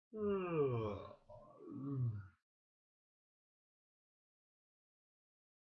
回声呻吟
描述：人呻吟着
标签： 呻吟
声道立体声